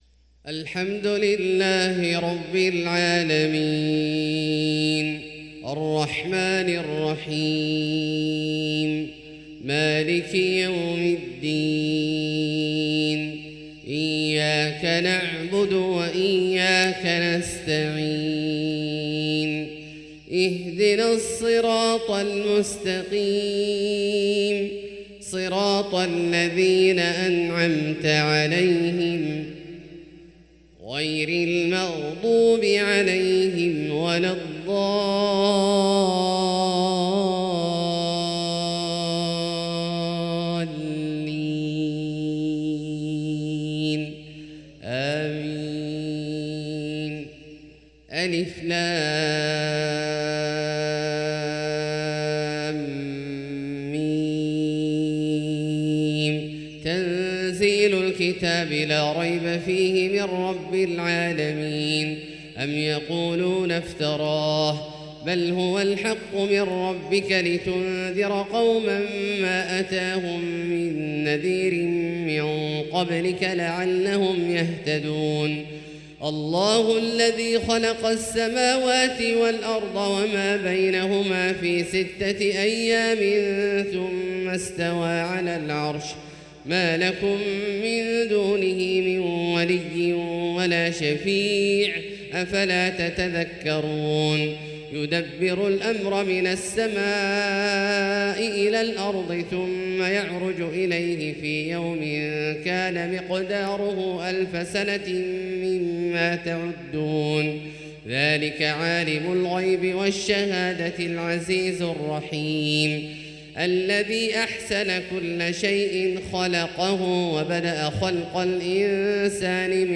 سورتي السجدة والإنسان بترتيل مميز للشيخ عبدالله الجهني من صلاة الفجر 26 رجب 1444هـ